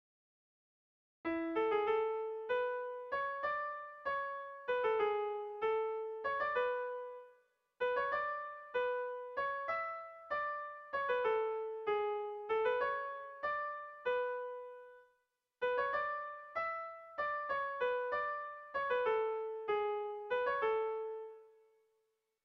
Erlijiozkoa
Eraso < Imotz < Larraun Leitzaldea < Nafarroa < Euskal Herria
ABD